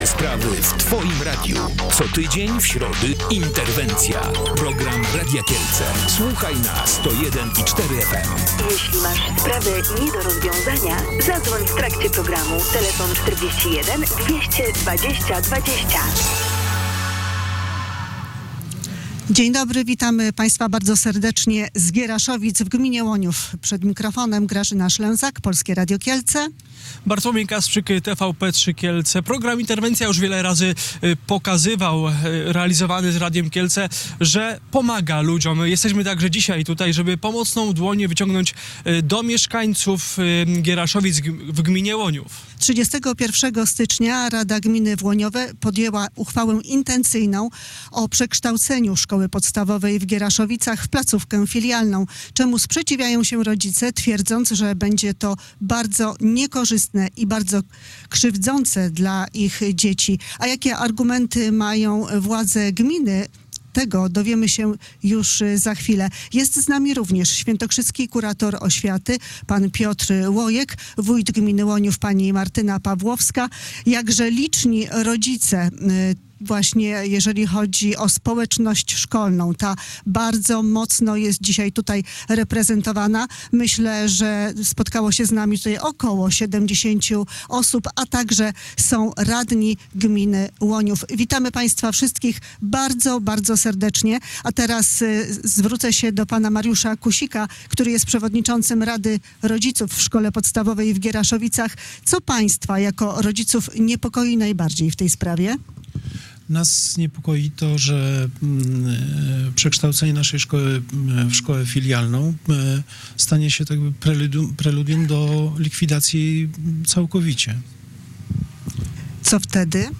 Rodzice uczniów ze Szkoły Podstawowej w Gieraszowicach w gminie Łoniów nie ustają w walce o pozostawienie tej placówki w obecnym kształcie. W środę (19 lutego) w programie Interwencja uczestniczyło około 70 matek i ojców. W spotkaniu wzięli też udział mieszkańcy tej i sąsiednich miejscowości.